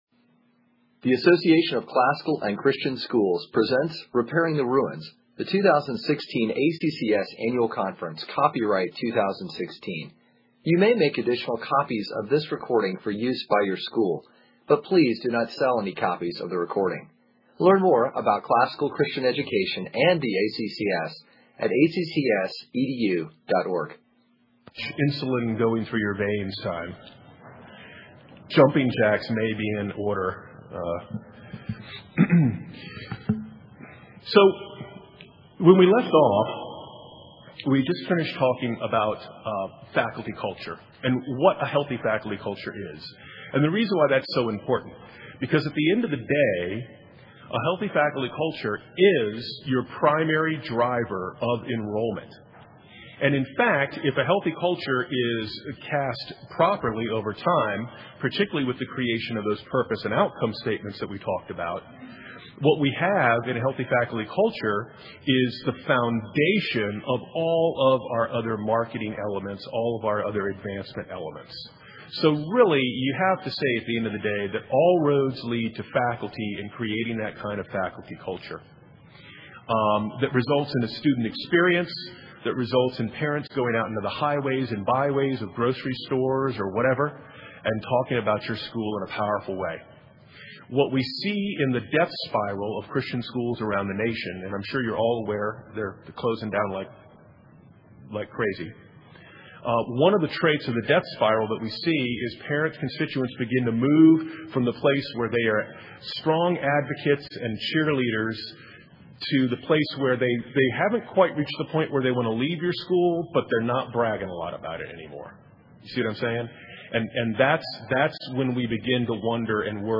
2016 Leaders Day Talk | 43:42:00 | All Grade Levels, Fundraising & Development, Marketing & Growth
Jan 10, 2019 | All Grade Levels, Conference Talks, Fundraising & Development, Leaders Day Talk, Library, Marketing & Growth, Media_Audio | 0 comments